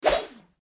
attack_blade_wp_2.mp3